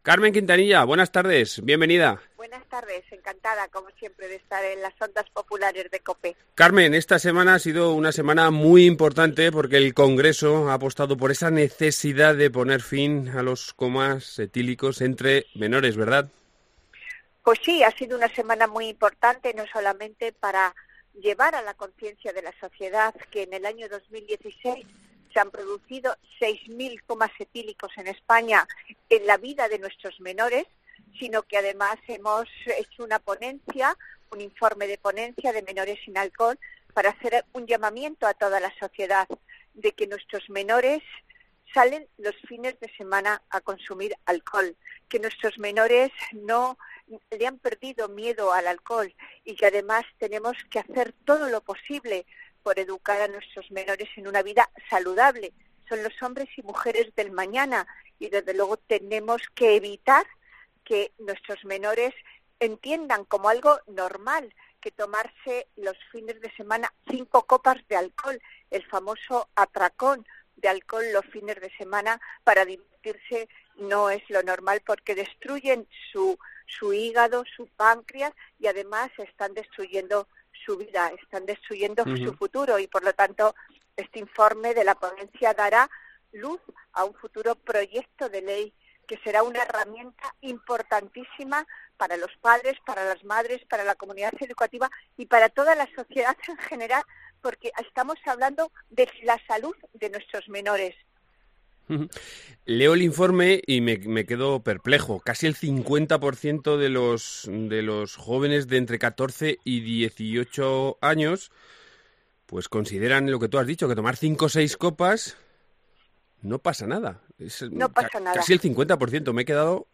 Numerosas medidas educativas, sociales y sanitarias para acabar con el botellón y los comas etílicos en menores. Hablamos con la diputada del PP Carmen Quintanilla que ha sido la presidenta de la comisión.